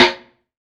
SNARE 045.wav